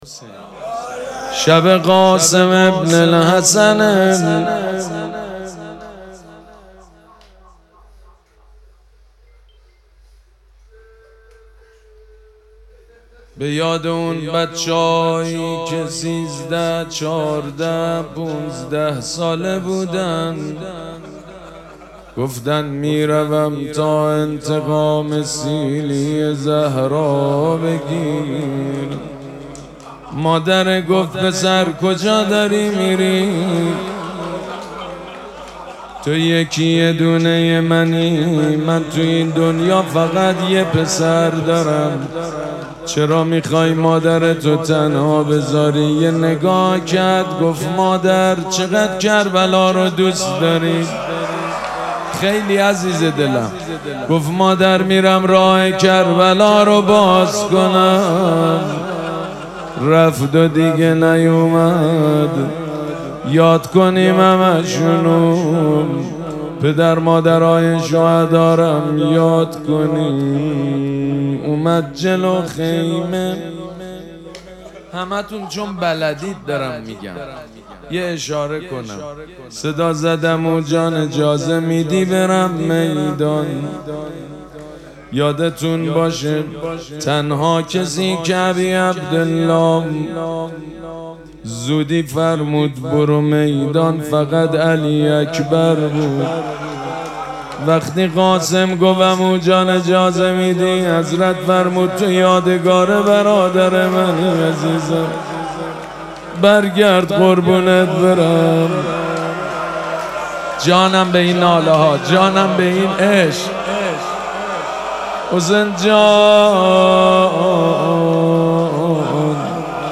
شب ششم محرم، سید مجید بنی فاطمه - روضه